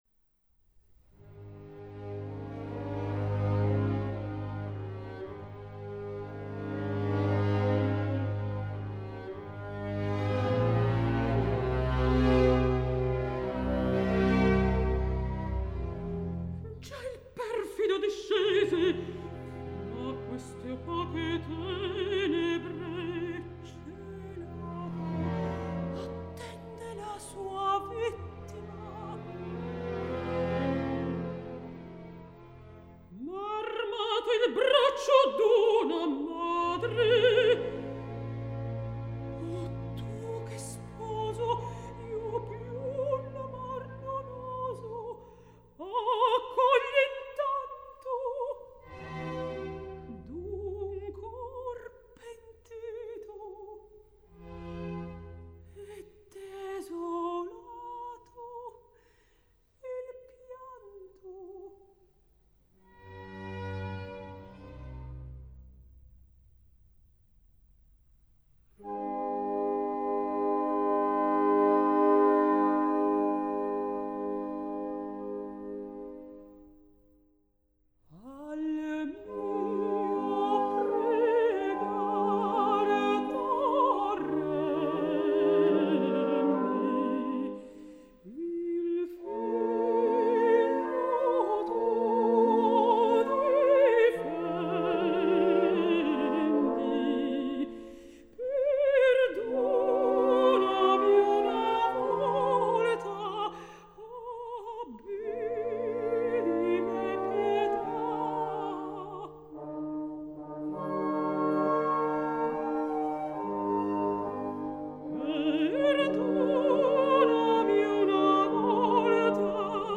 mezzosoprano